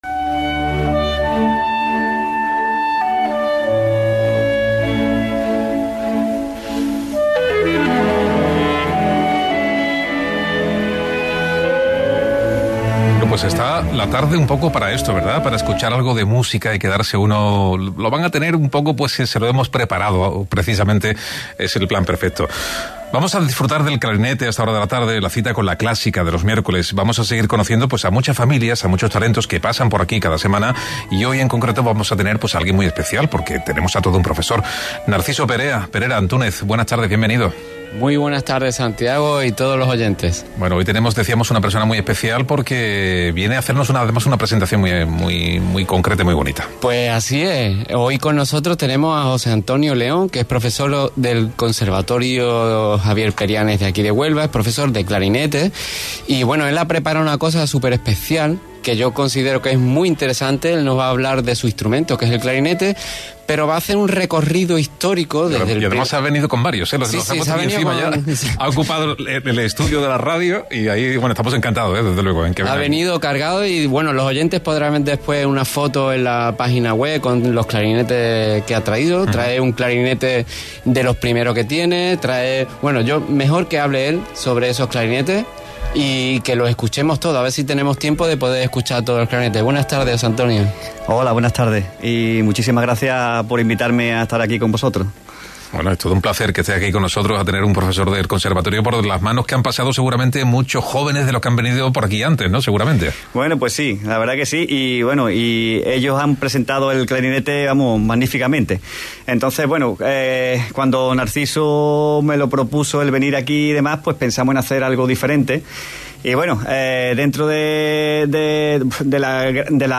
En los estudios de la Cadena SER